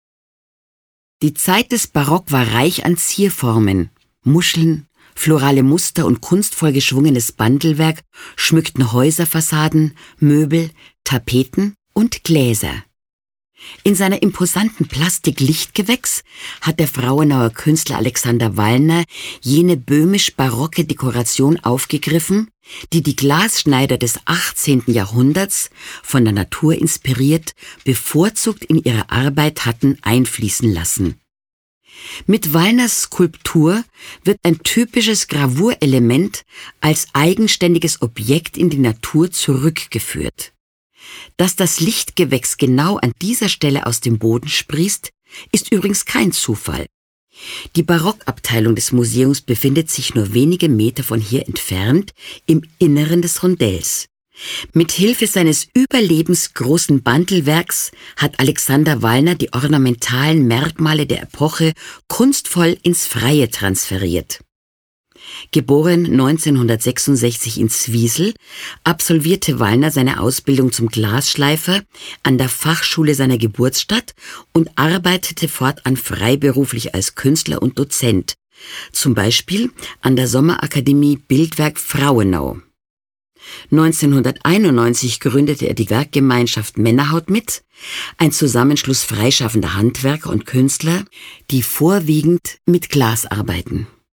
Sprecherin: Veronika von Quast